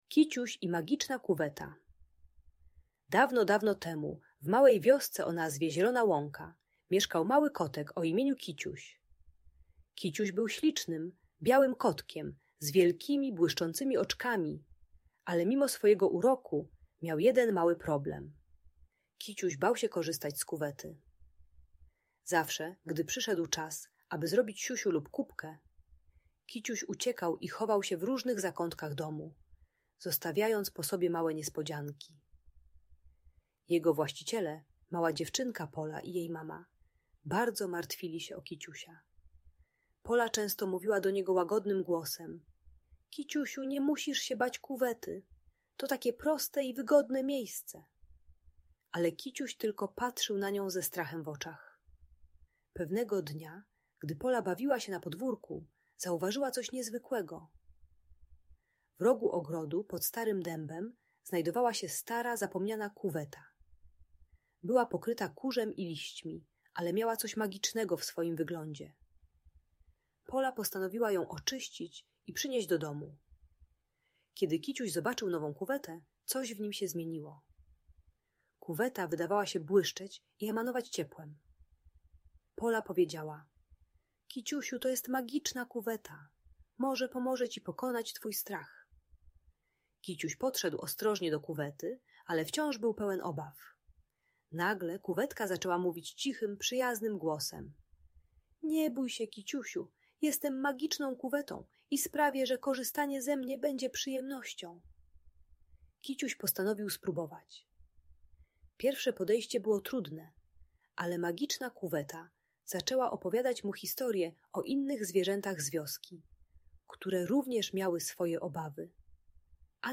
Historia Kiciusia - Trening czystości | Audiobajka